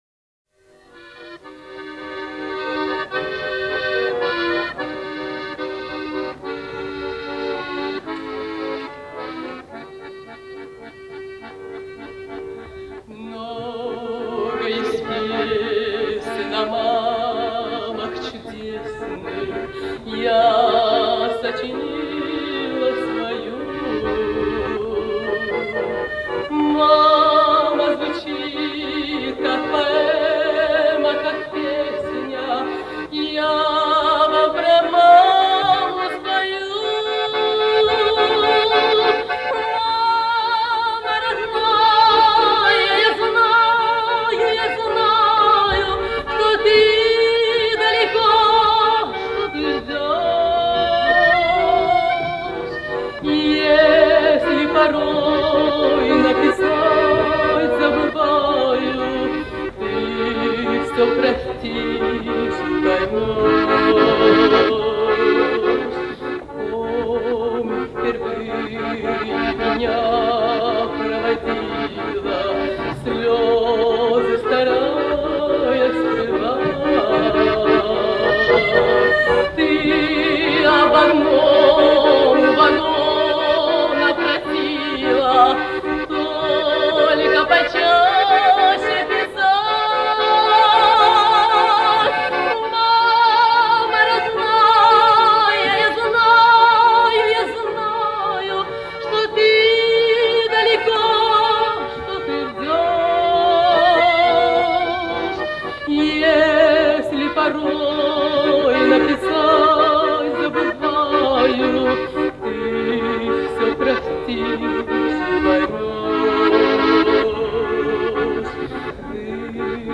Под баян